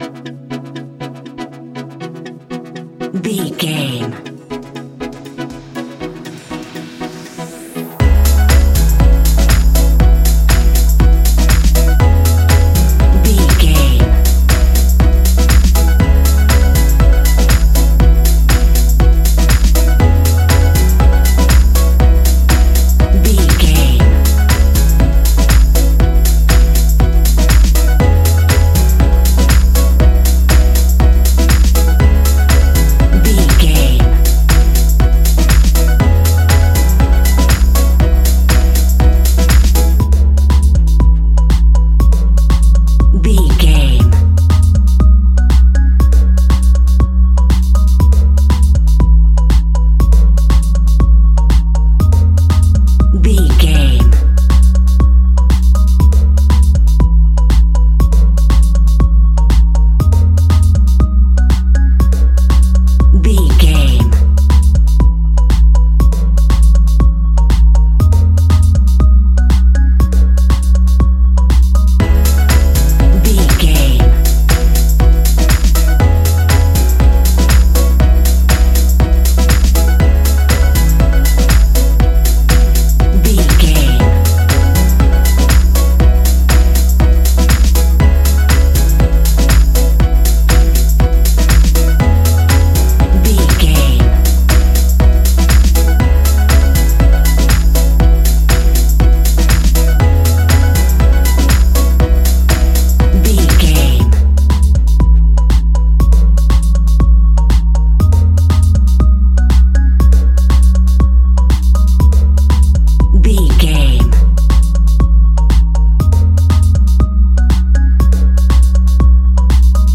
Ionian/Major
A♭
house
electro dance
synths
techno
trance